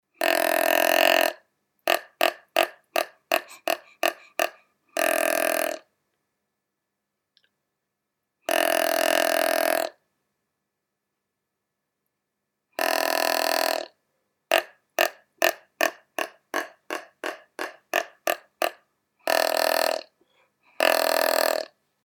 White-Tailed Deer
Voice
White-tailed deer produce several types of vocalizations such as grunts, wheezes, and bleats.
Injured deer utter a startlingly loud "blatt" or bawl. Whistles or snorts of disturbed white-tailed deer are the most commonly heard sounds.
white-tailed-deer-call.mp3